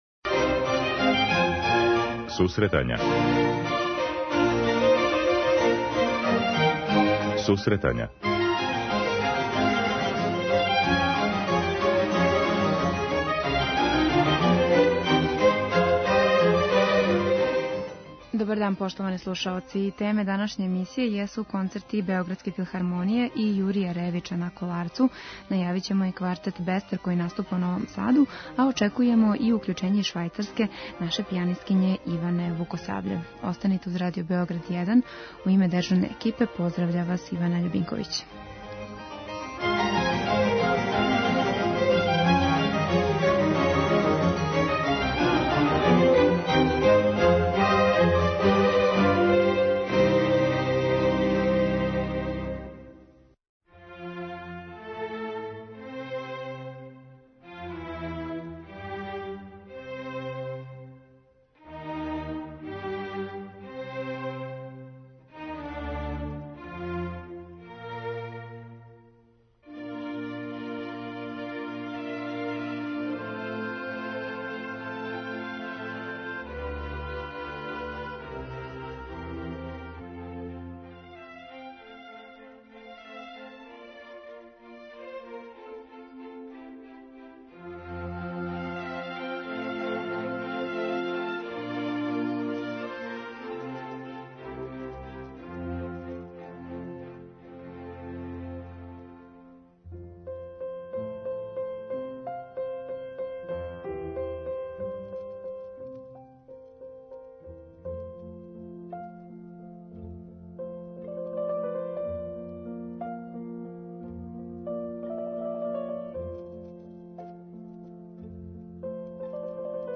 преузми : 10.80 MB Сусретања Autor: Музичка редакција Емисија за оне који воле уметничку музику.